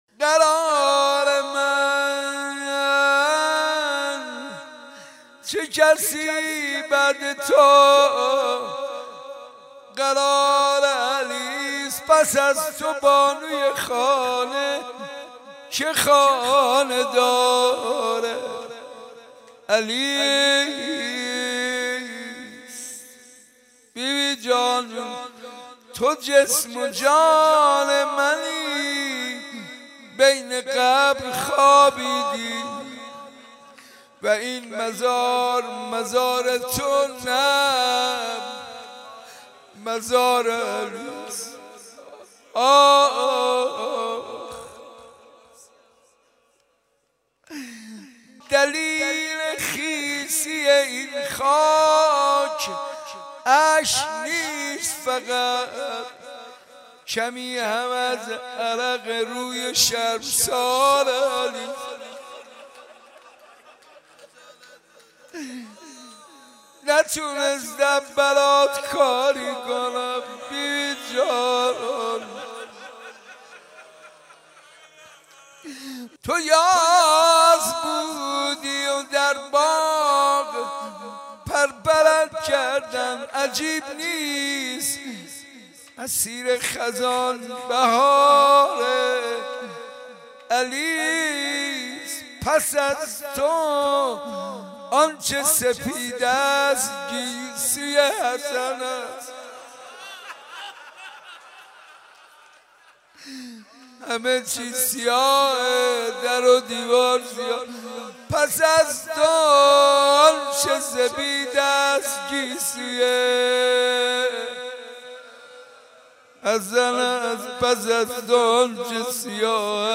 مراسم زیارت عاشورا صبح سه شنبه در حسینیه صنف لباس فروشان